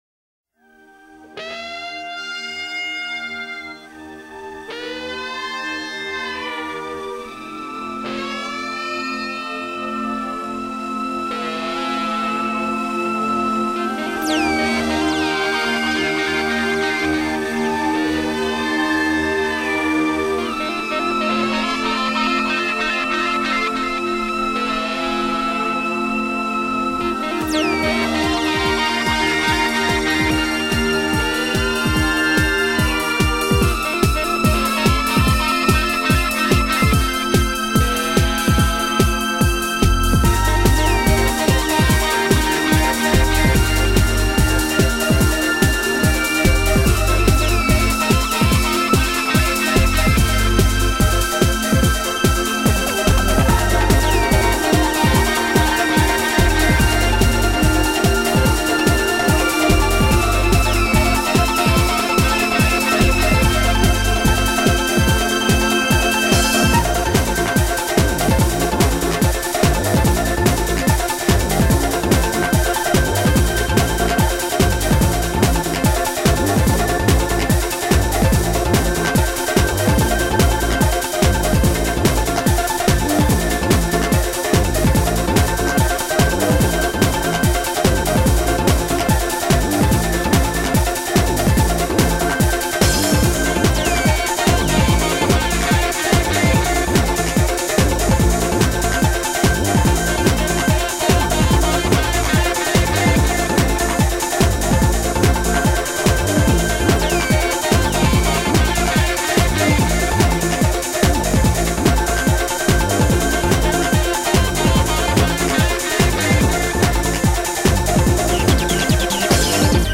On le devine crier, gémir, a l'agonie... sublime